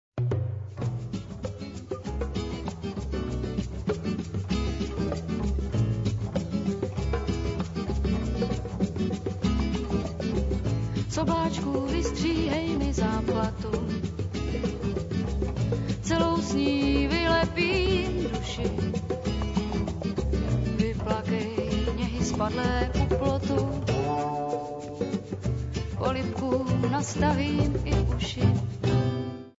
folkrock group
contrabass